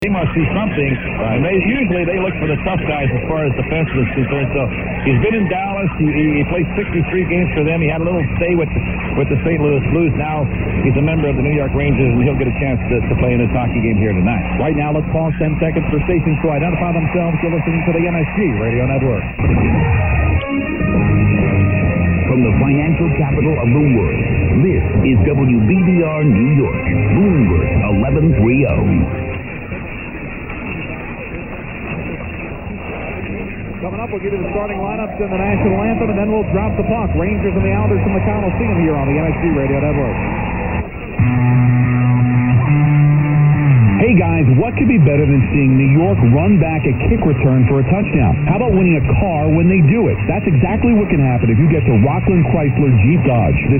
It was WDFN Detroit, loud and clear at the standard WBBR level.
111012_0700_1130_wdfn_detroit_booming.mp3